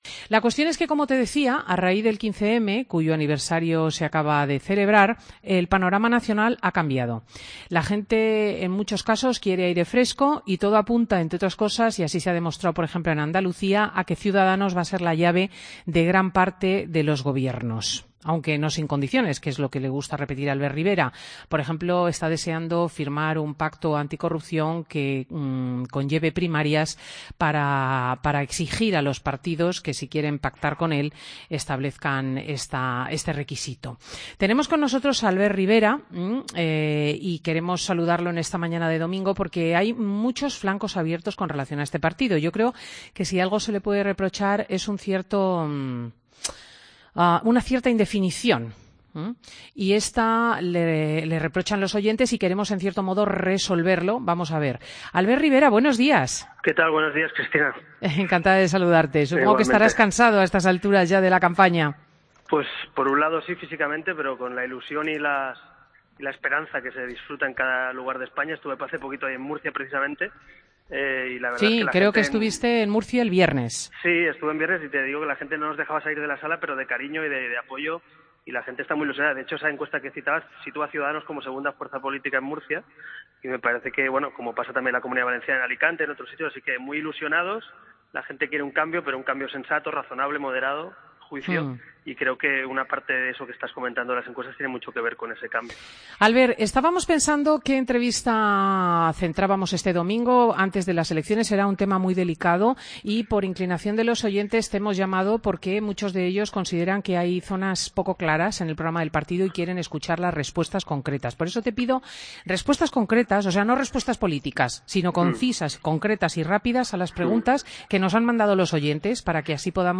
Escucha la entrevista a Albert Rivera en "Fin de semana"